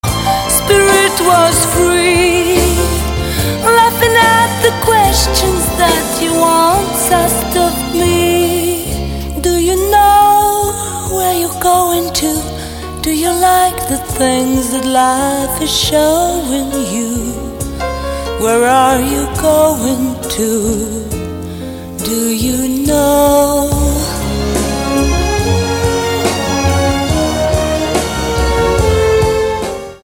Звучит примерно так (демка)